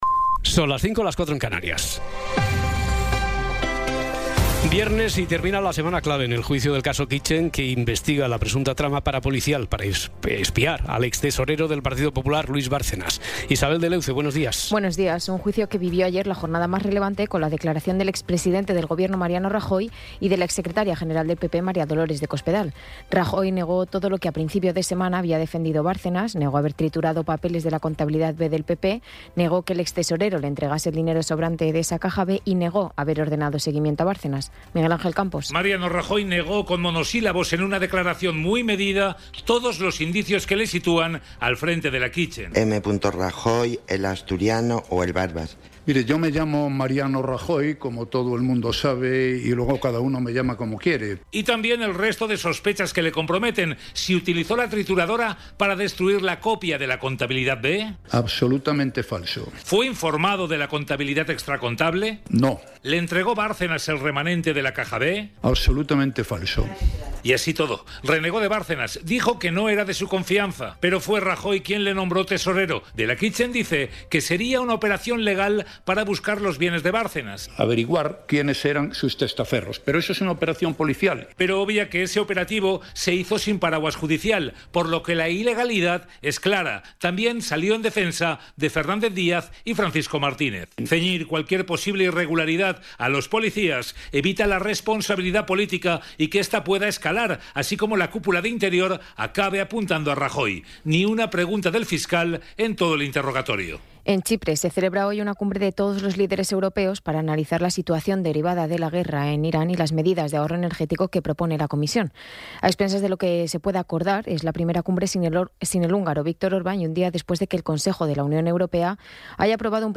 Resumen informativo con las noticias más destacadas del 24 de abril de 2026 a las cinco de la mañana.